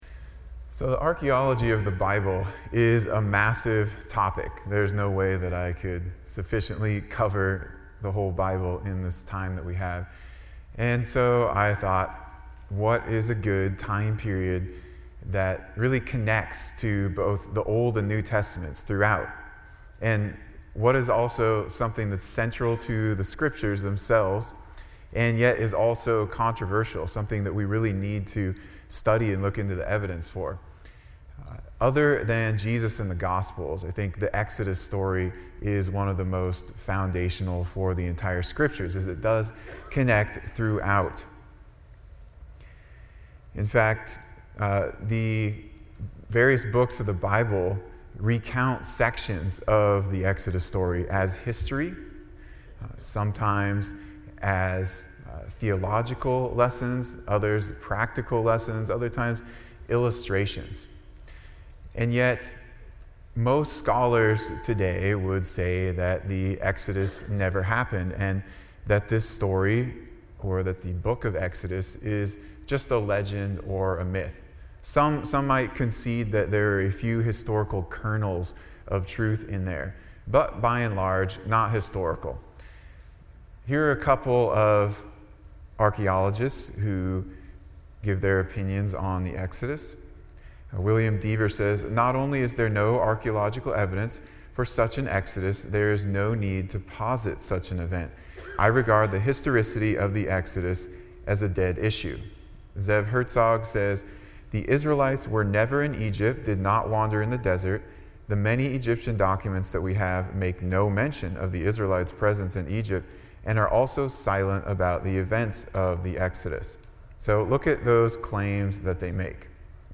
Apologetics Conference